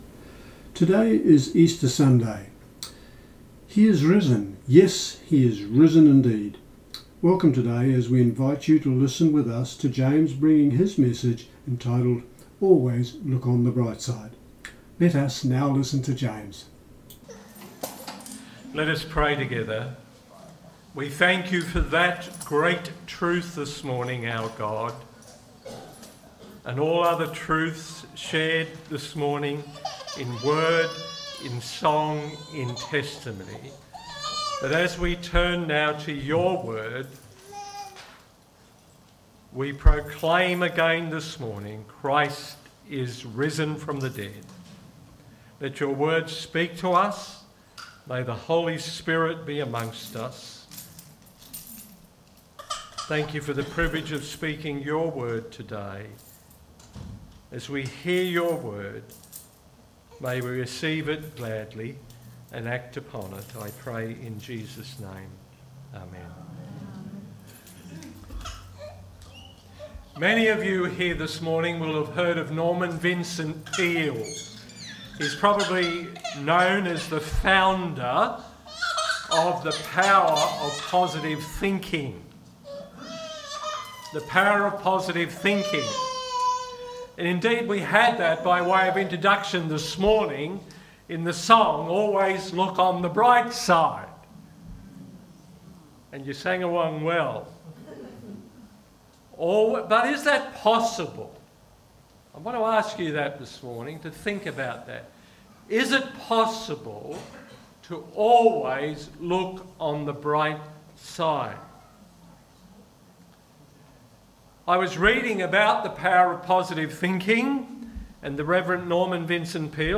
This is the Easter Sunday Message Always look on the Bright side. with the Scripture readings coming from Romans 12:9-13 and 1 Peter 1:3